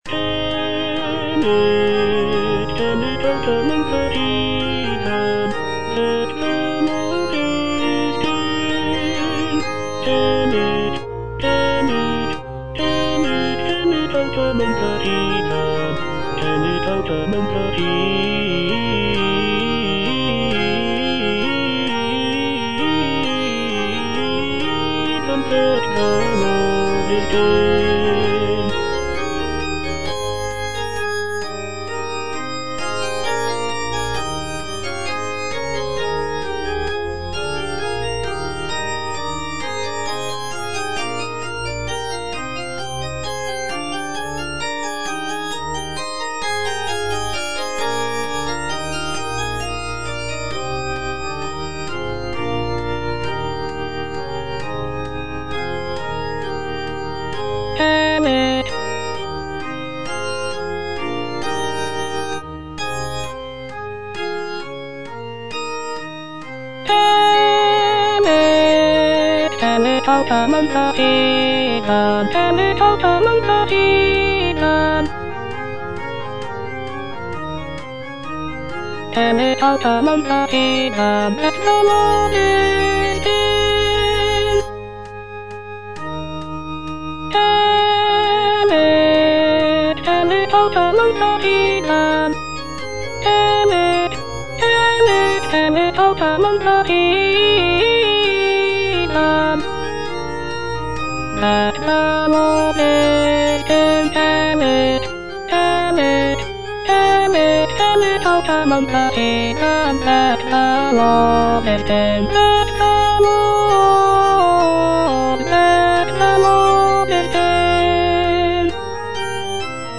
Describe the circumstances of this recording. (A = 415 Hz)